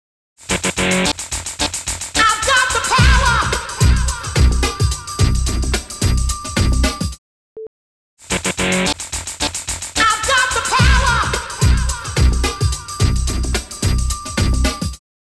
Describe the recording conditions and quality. The stereo-image has been altered.